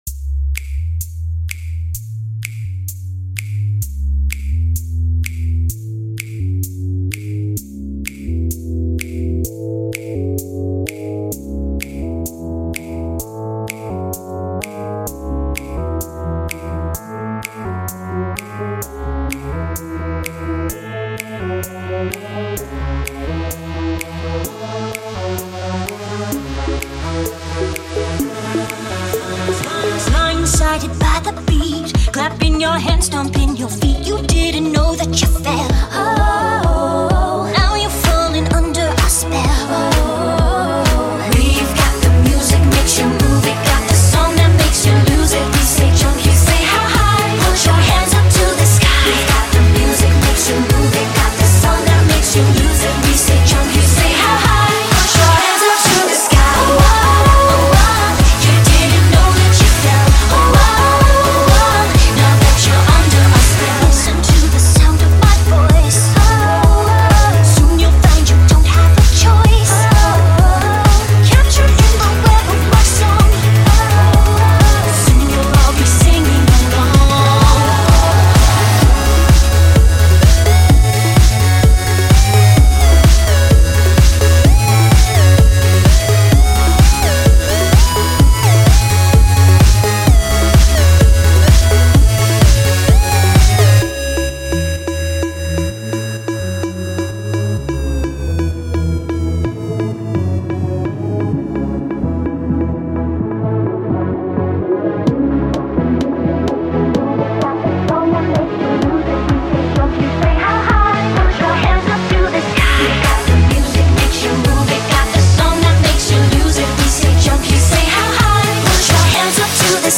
Dirty Techno remix